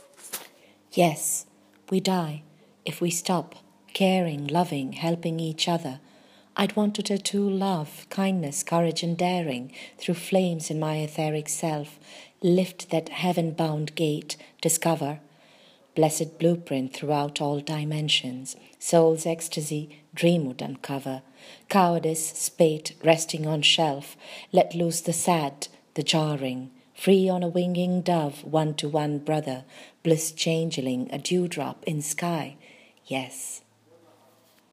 Reading of the poem